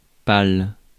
Ääntäminen
France (Paris): IPA: /pal/